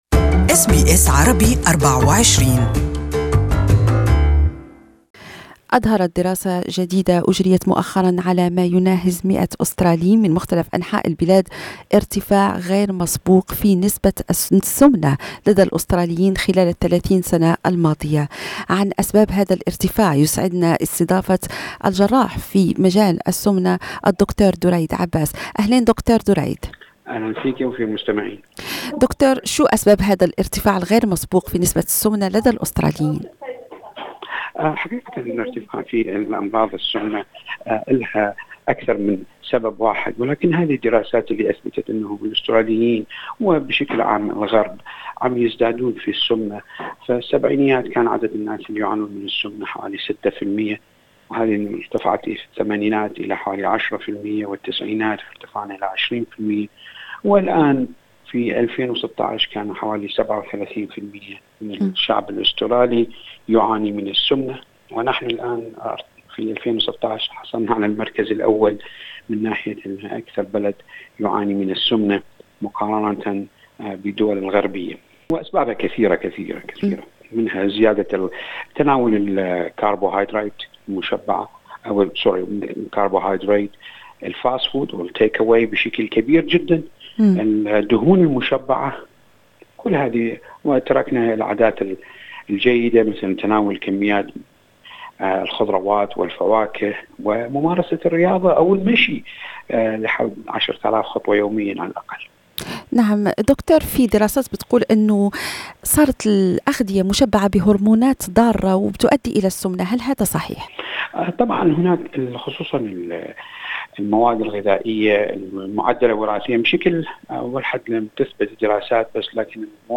رأي طبيب: نتائج السمنة وخيمة من بينها وفاة الشباب في سن مبكر